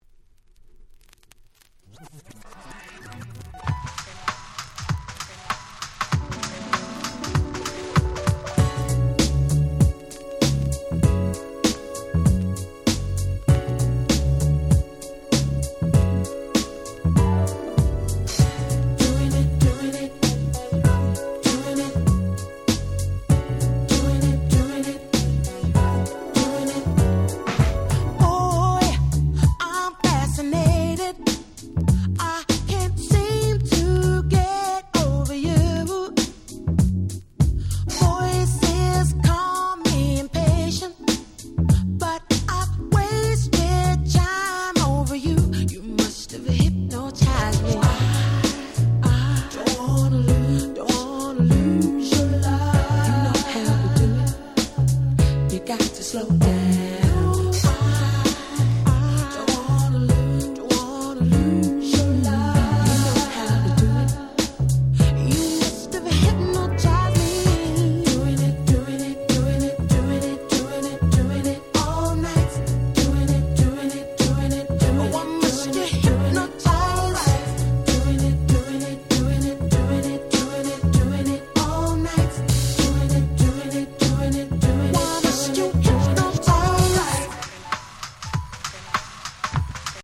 UK R&B Classic LP !!